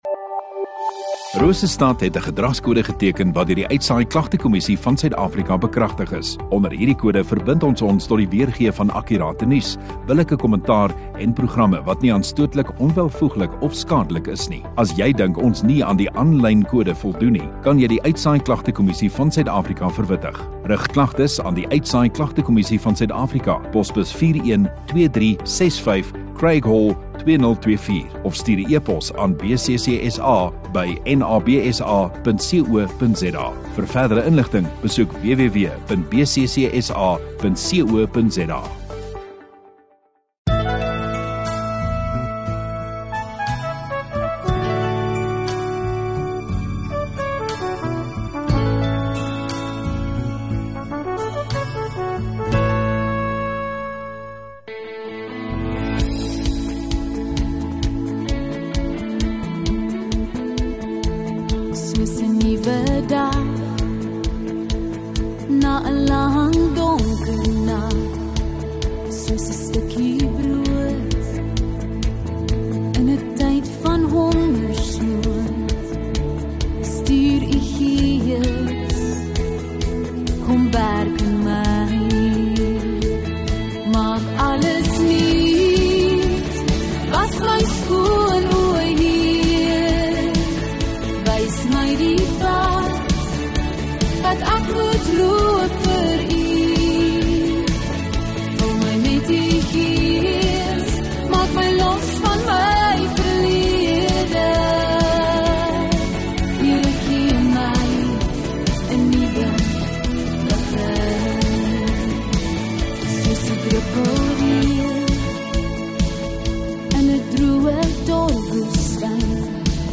4 May Saterdag Oggenddiens